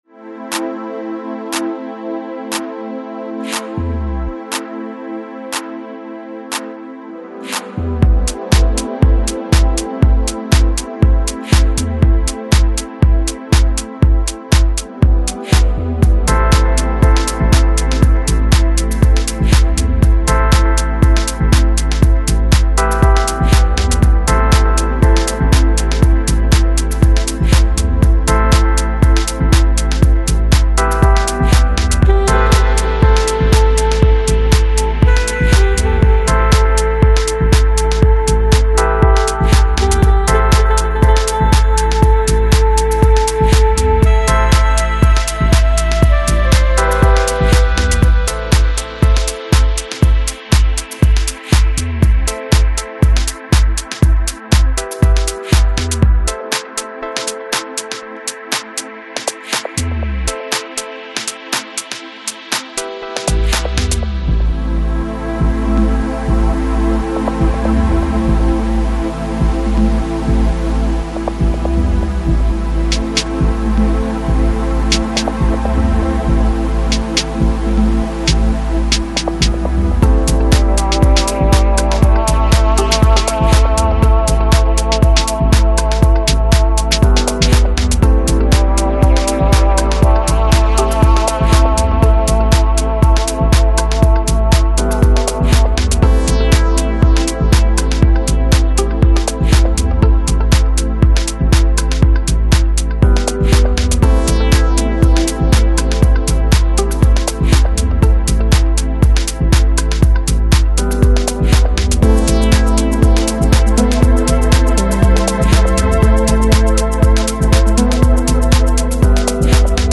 Electronic, Lounge, Chill Out, Chill House Год издания